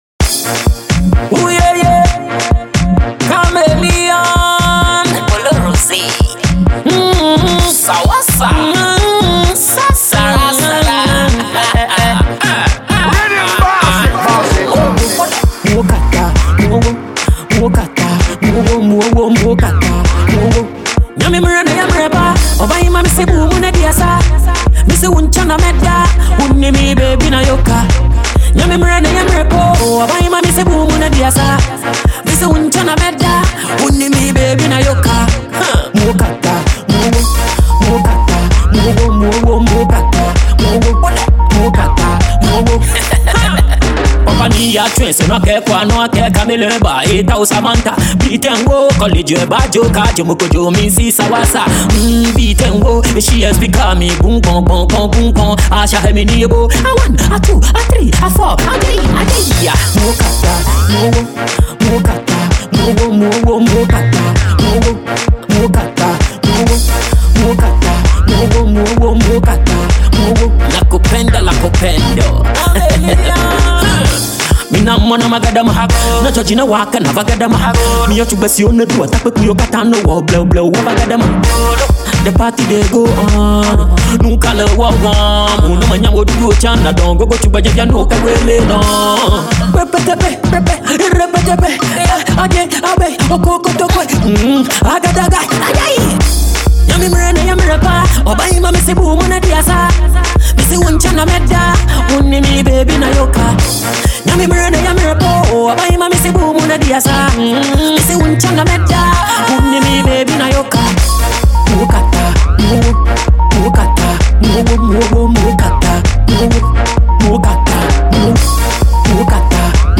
Reggae/Dancehall & Afrobeats